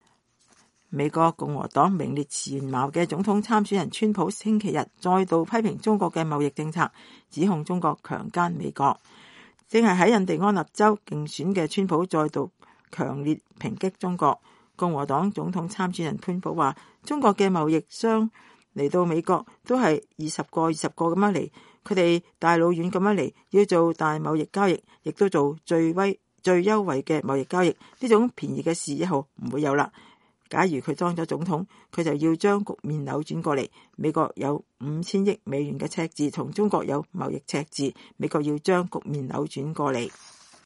正在印第安納州競選的川普再度強烈抨擊中國。